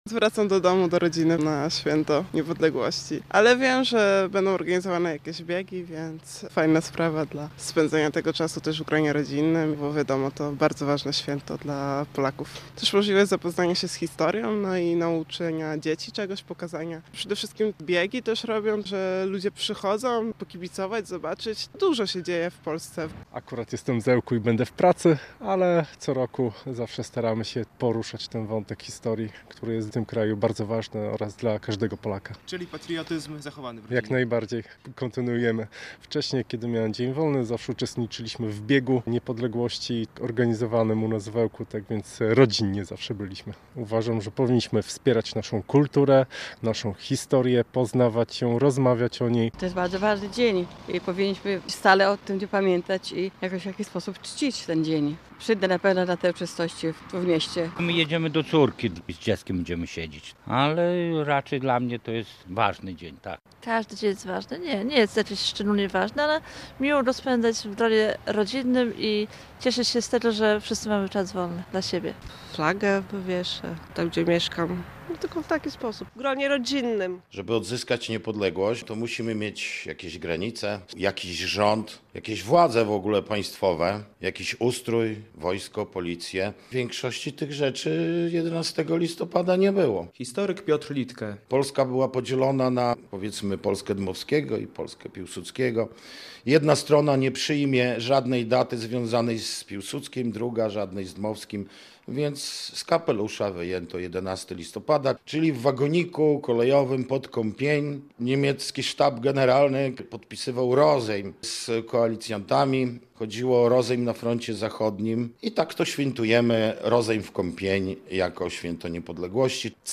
Jak obchodzimy Narodowe Święto Niepodległości? - relacja